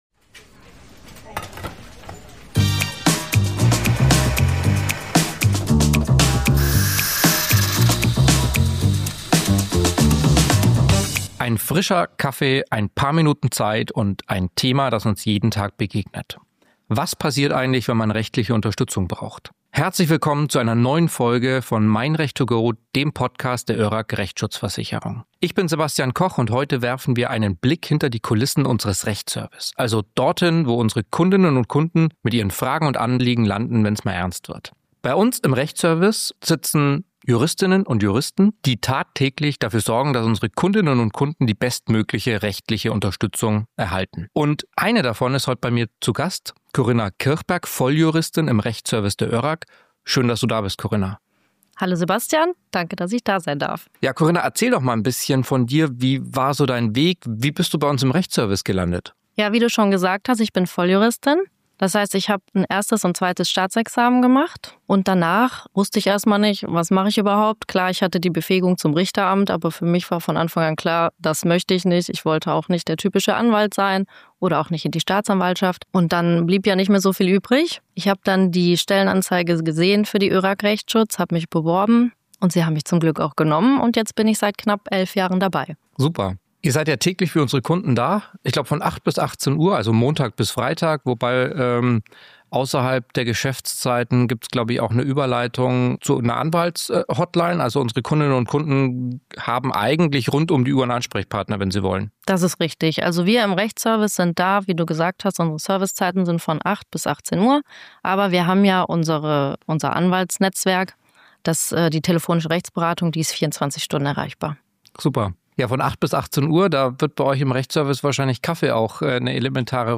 Im Gespräch wird deutlich: Im Rechtsservice geht es um weit mehr als um Paragrafen und Deckungsfragen.